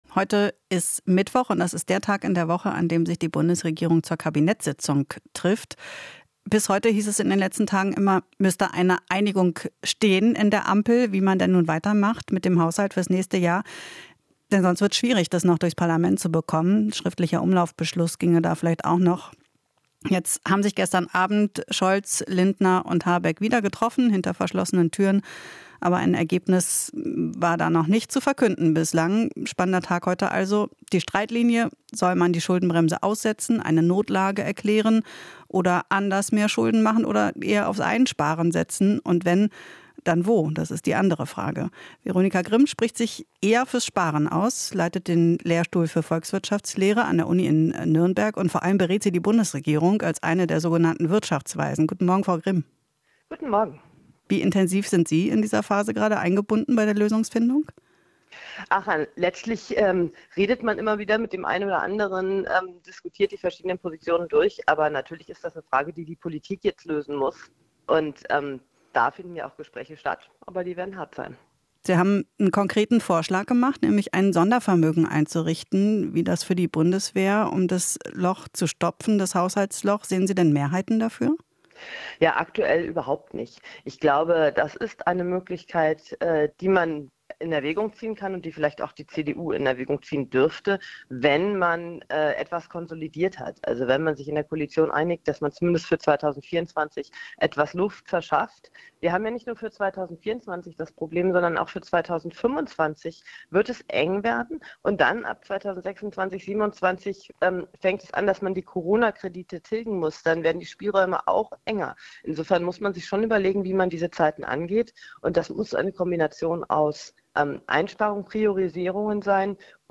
Interview - Wirtschaftsweise Grimm: "Langfristig tragfähige Staatsfinanzen sind wichtig"